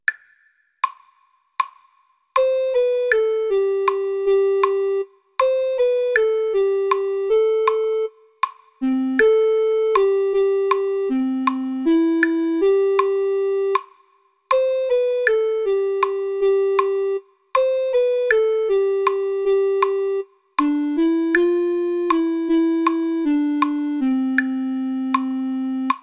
Arreglo para flauta